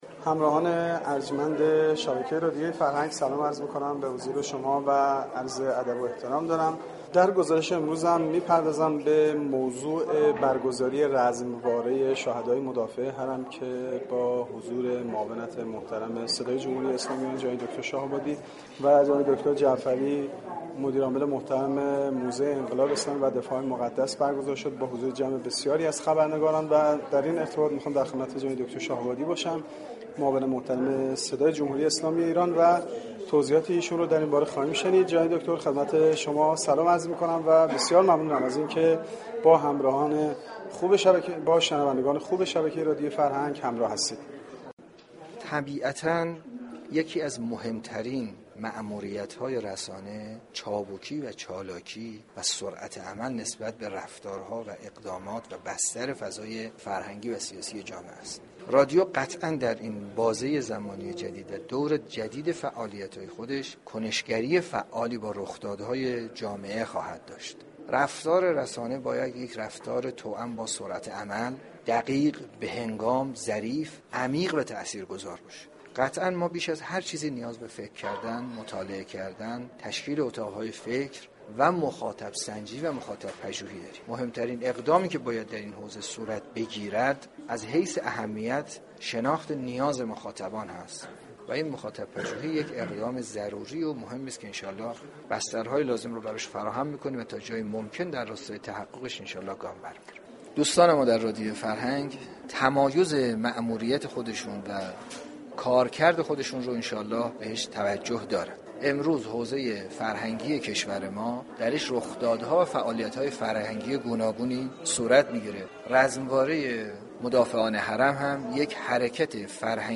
دكتر حمید شاه آبادی در گفتگو با خبرنگار شبكه ی فرهنگ چابكی و چالاكی را از ماموریتهای مهم یك رسانه دانسته و اظهار داشتند :